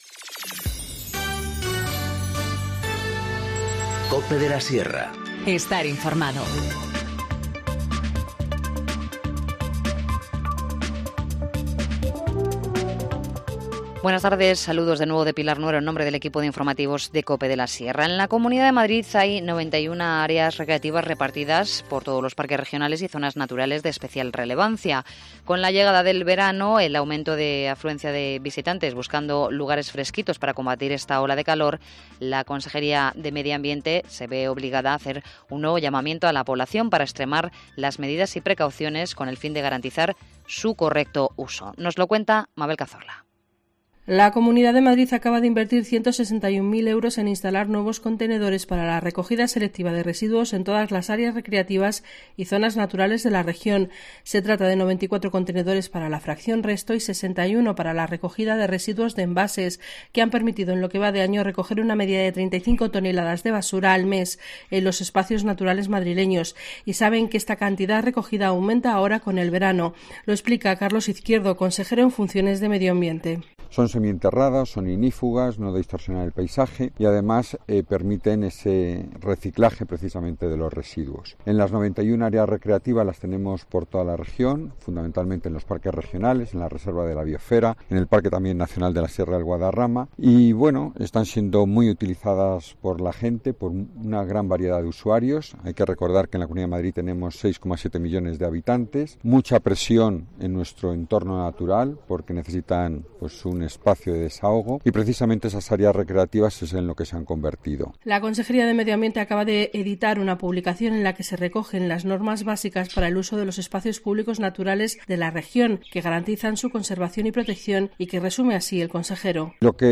Informativo Mediodía 27 junio 14:50h